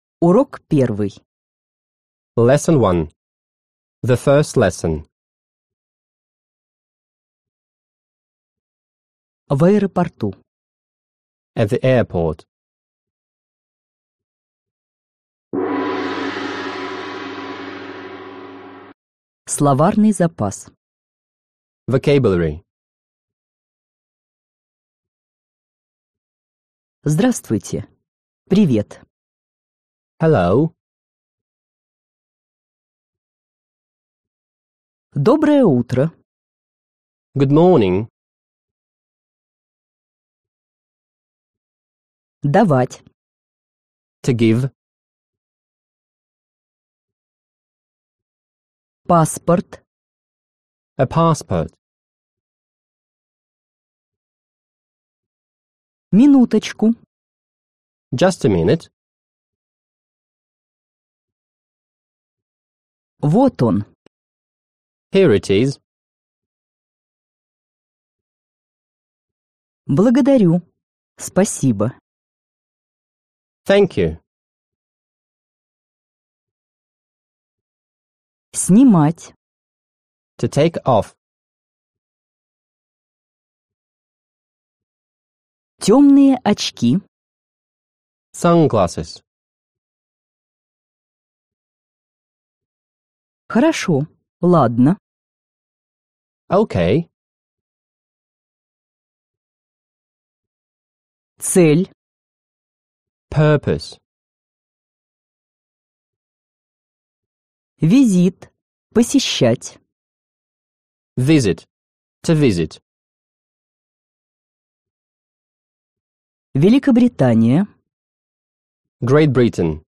Aудиокнига Английский язык за 2 недели Автор Коллектив авторов Читает аудиокнигу Профессиональные дикторы.